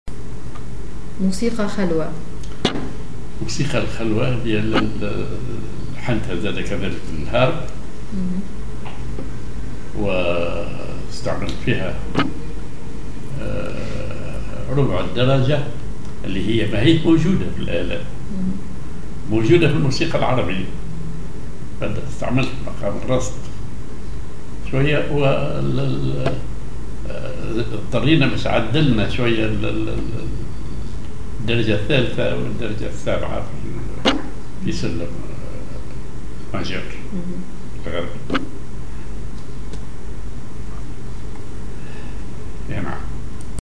Maqam ar راست
معزوفة